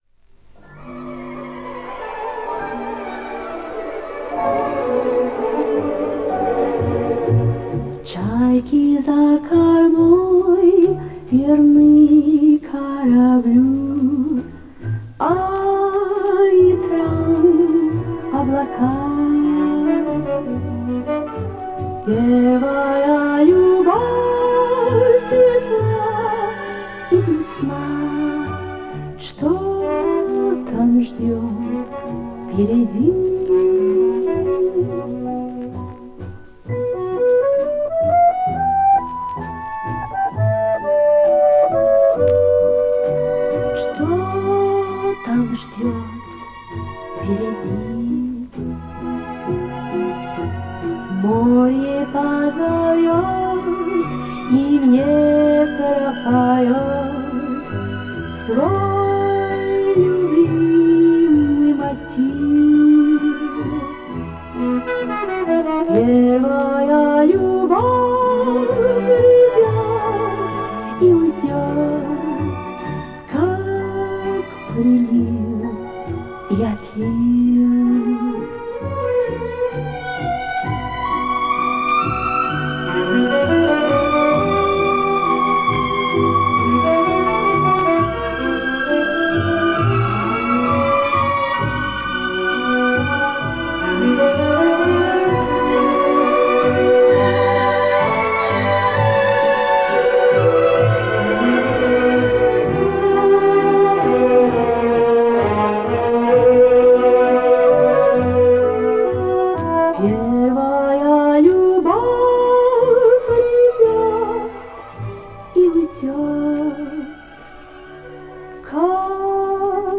Вот такой стереовариант с помощью простенькой программы :